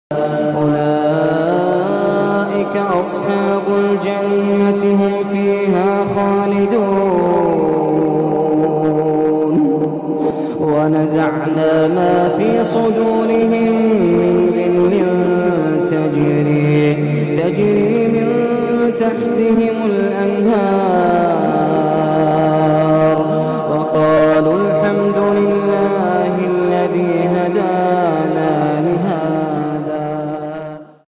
قرآن كريم #